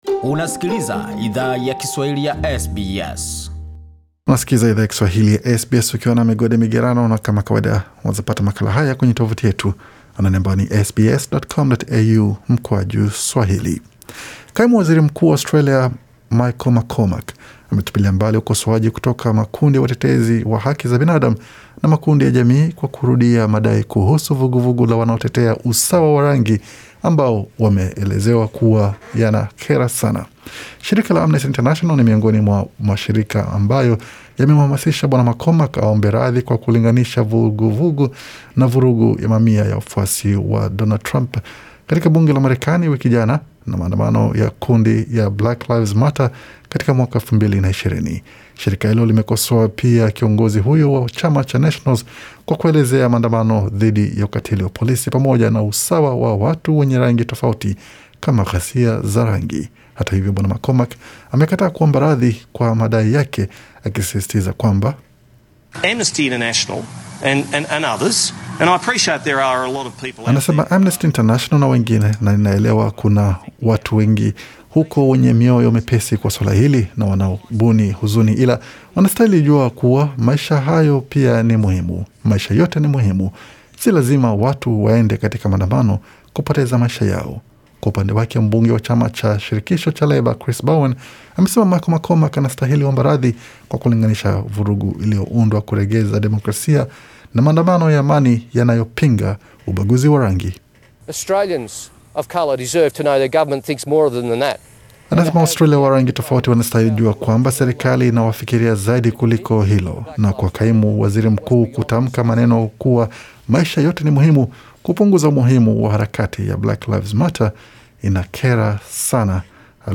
Taarifa ya habari 12 Januari 2021